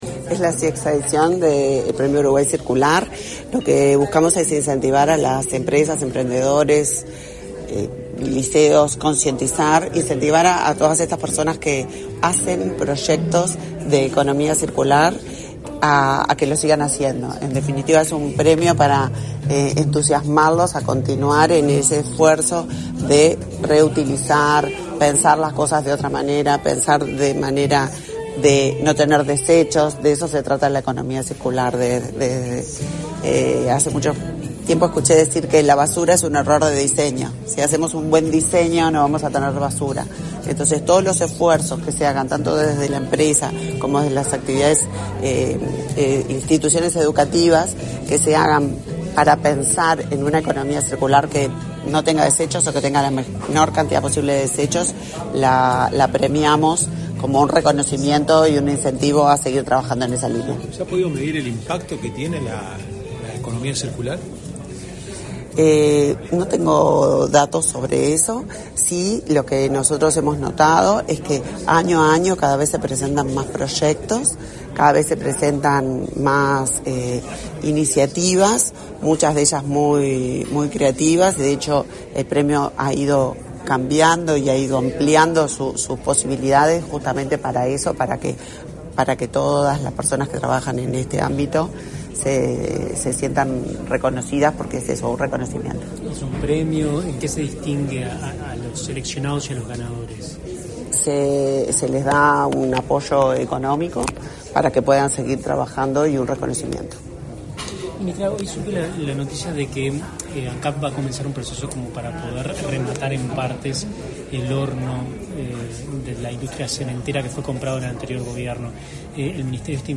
Declaraciones a la prensa de la ministra de Industria, Energía y Minería, Elisa Facio
Declaraciones a la prensa de la ministra de Industria, Energía y Minería, Elisa Facio 20/06/2024 Compartir Facebook X Copiar enlace WhatsApp LinkedIn Tras participar en el lanzamiento de la edición 2024 del Premio Uruguay Circular, este 20 de junio, la ministra de Industria, Energía y Minería, Elisa Facio, realizó declaraciones a la prensa.